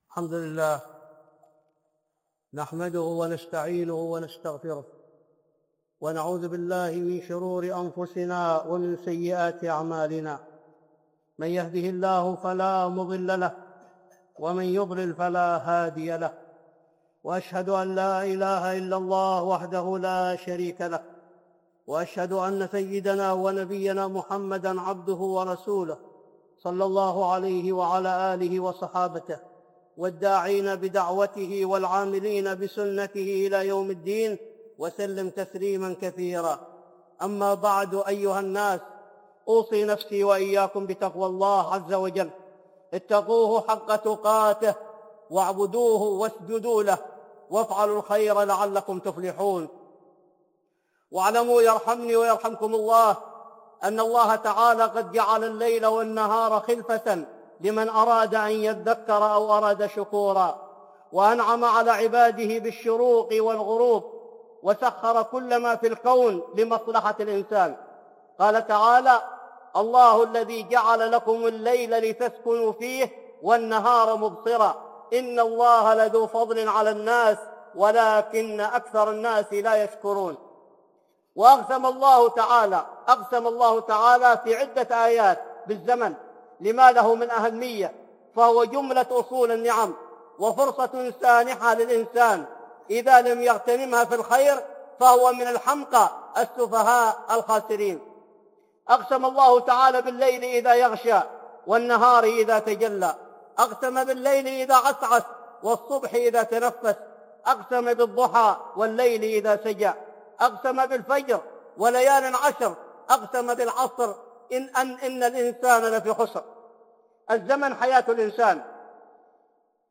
(خطبة جمعة) إغتنام الوقت